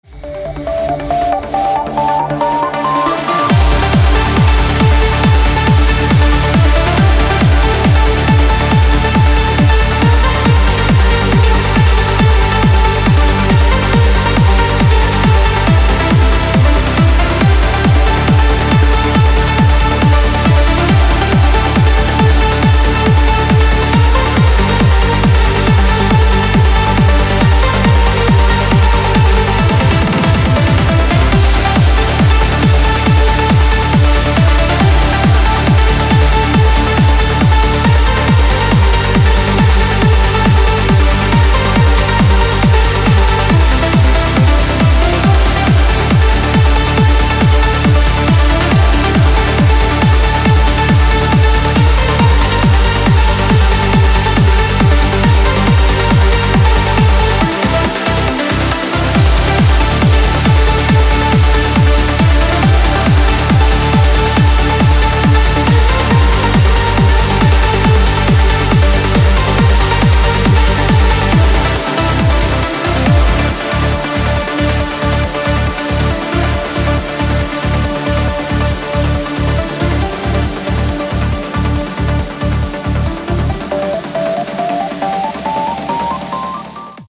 • Качество: 160, Stereo
громкие
dance
Electronic
без слов
энергичные
Trance
Uplifting trance